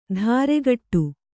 ♪ dhāregaṭṭu